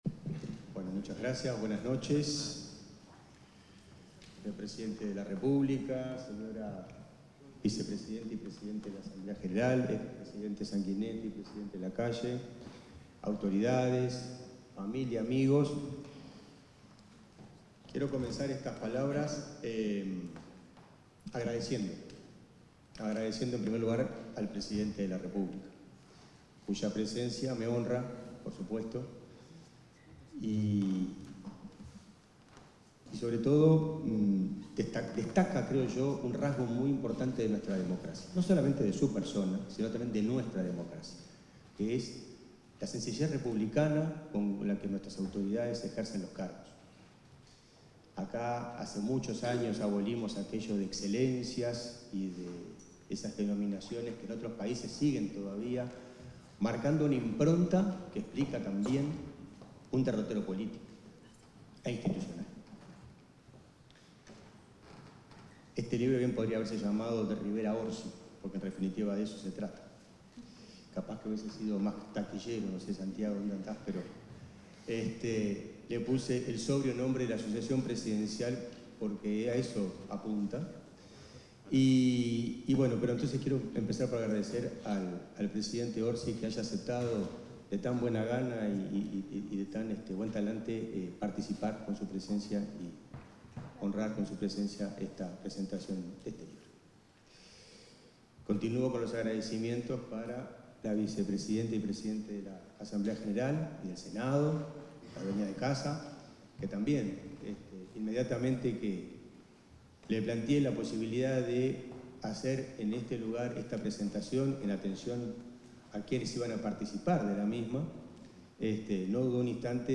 El ministro de la Corte Electoral José Garchitorena expuso en la presentación de su libro "La sucesión presidencial", en el Palacio Legislativo.